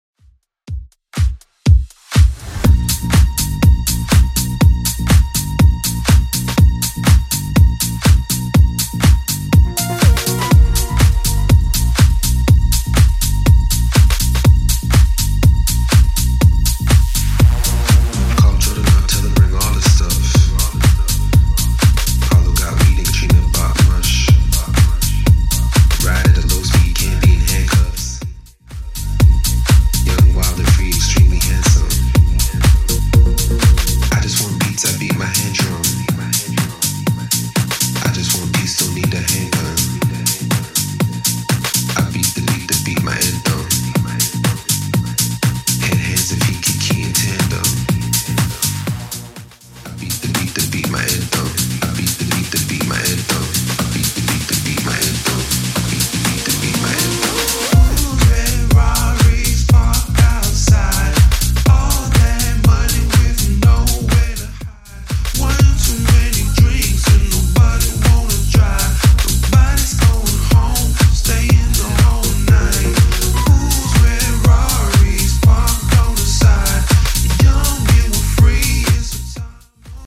Genre: 90's Version: Clean BPM: 92